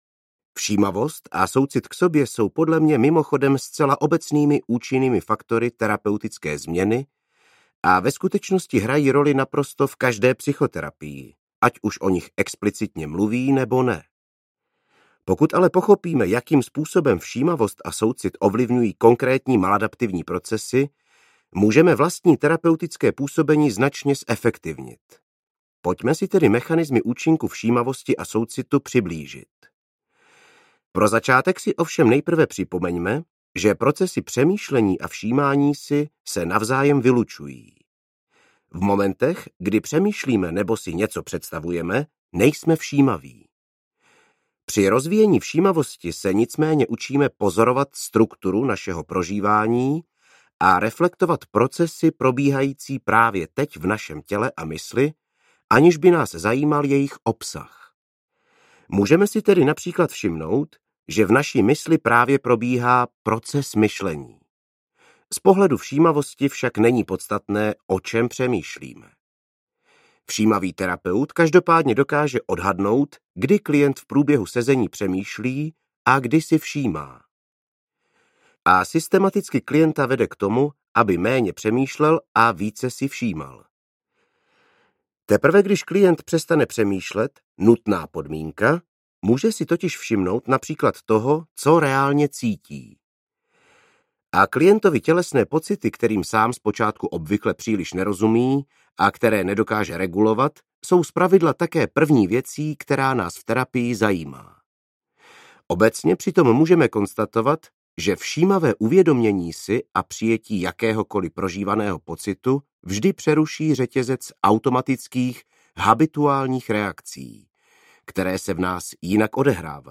Všímavost a Já audiokniha
Ukázka z knihy